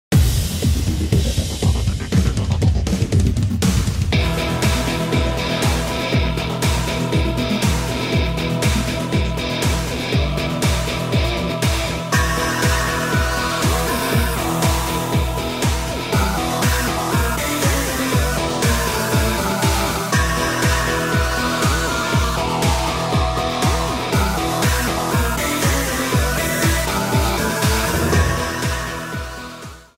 Trimmed to 30 seconds, with a fade-out effect